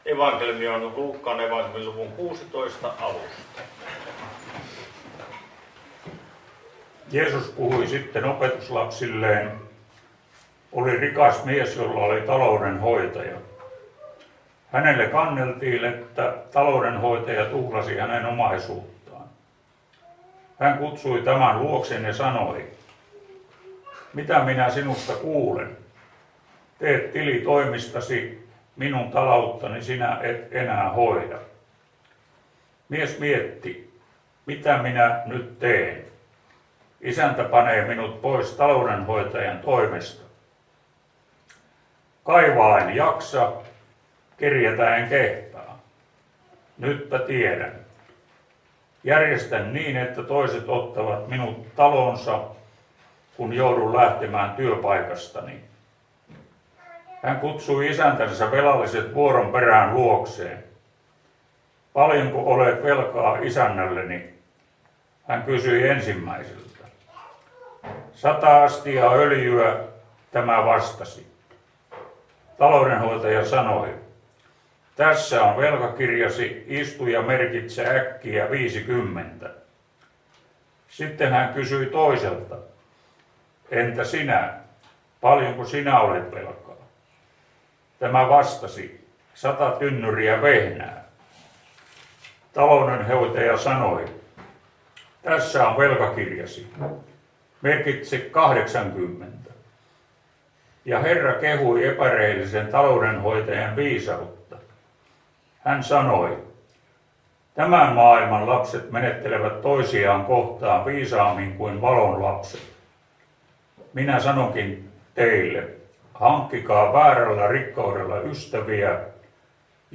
Lappeenranta